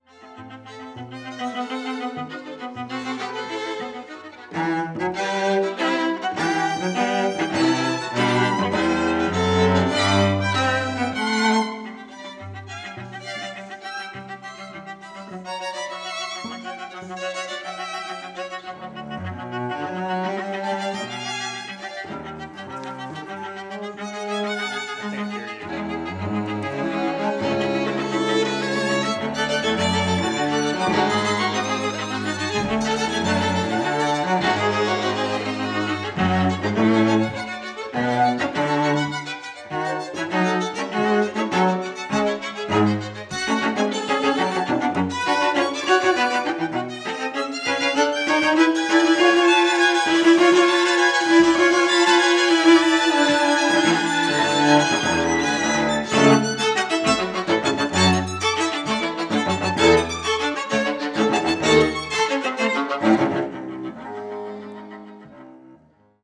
Tetrahedral Ambisonic Microphone
Recorded February 4, 2010, Jessen Auditorium, University of Texas at Austin. Quartet practicing for recording session.
Credits: Aeolus String Quartet.